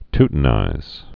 (ttn-īz, tyt-)